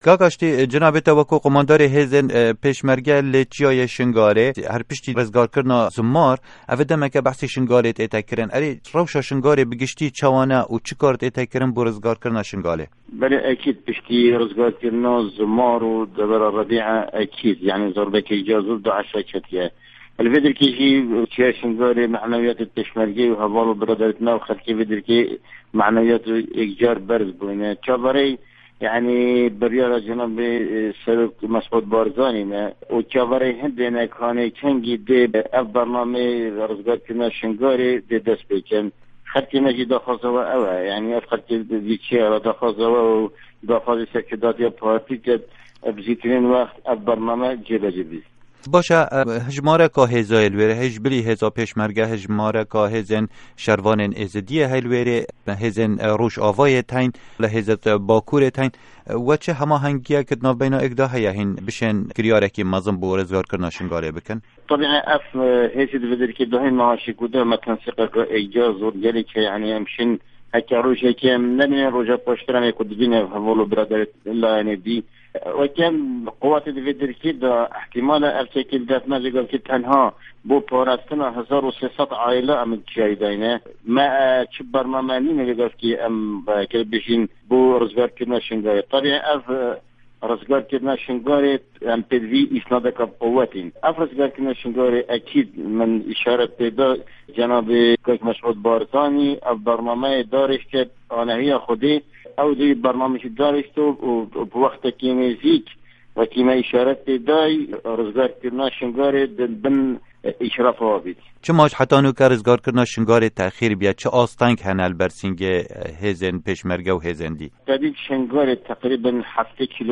hevpeyvînekê